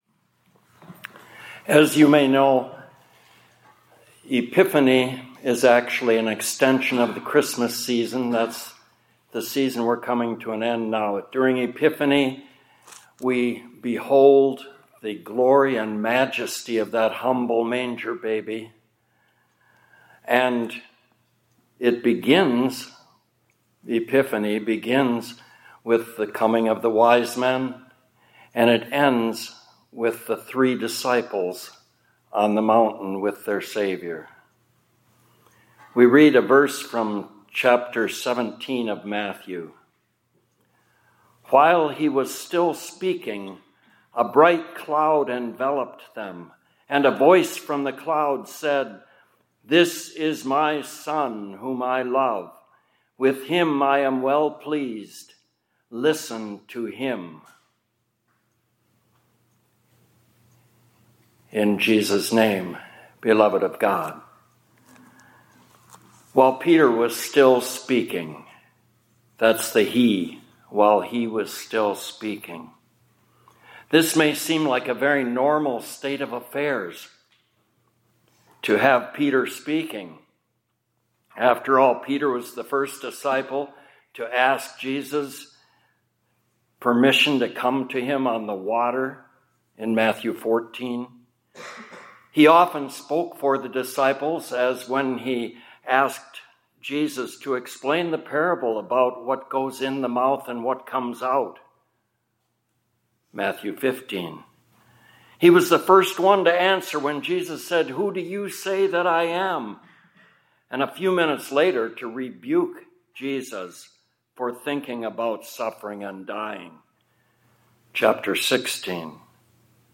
2026-02-12 ILC Chapel — This is My Son — Hear Him